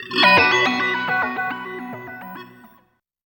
69 GTR 4  -R.wav